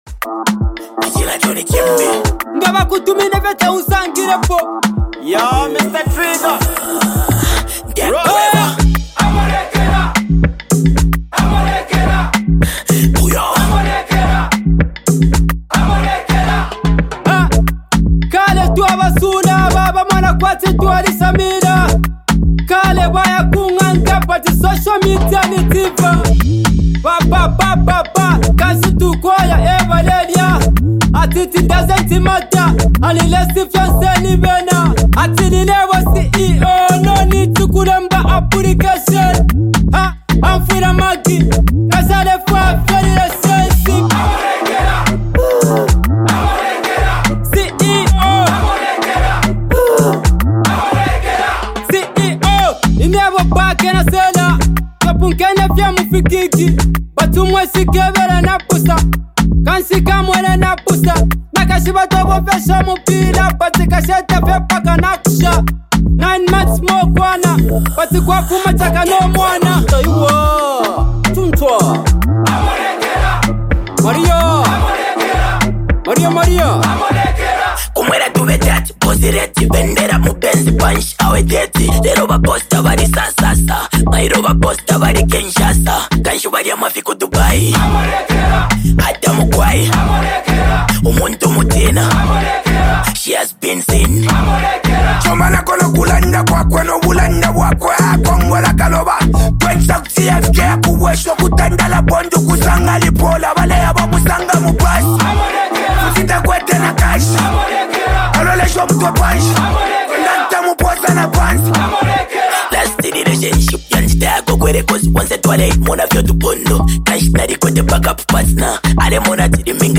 is a heartfelt and emotionally charged song